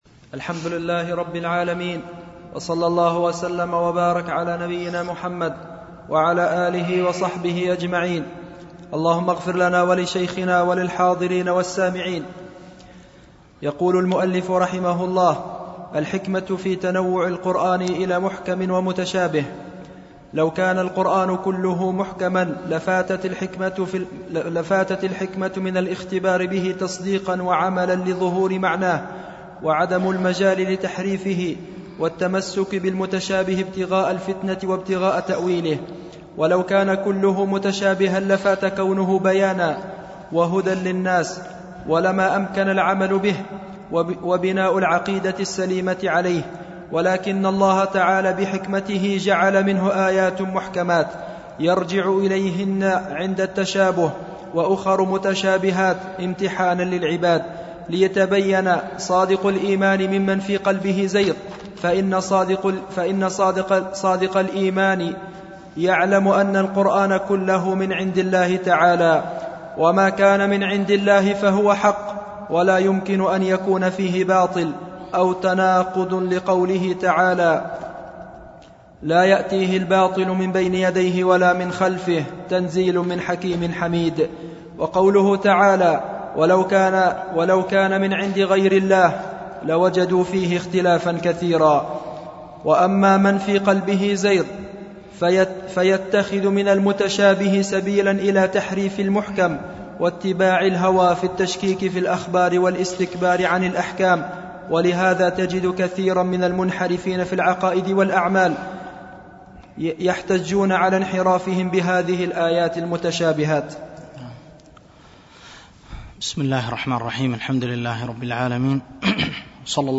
شرح أصول في التفسير ـ الدرس التاسع
الألبوم: دروس مسجد عائشة